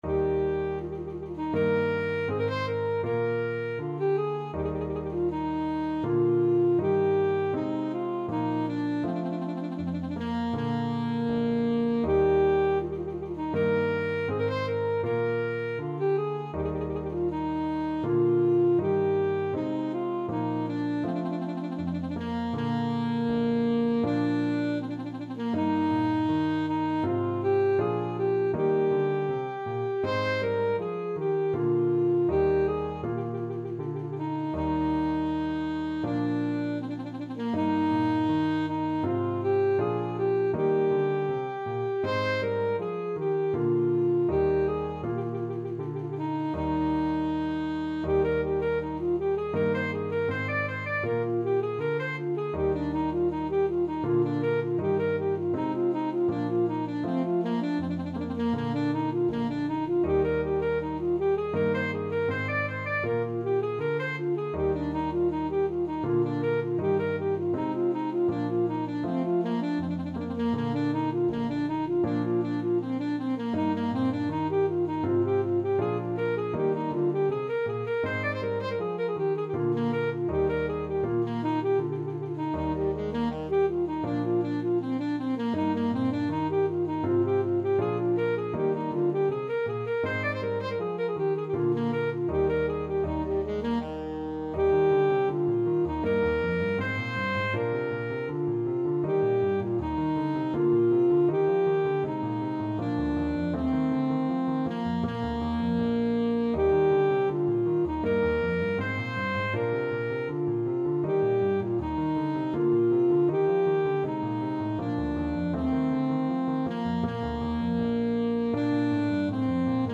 Alto Saxophone version
Allegretto =80
4/4 (View more 4/4 Music)
Classical (View more Classical Saxophone Music)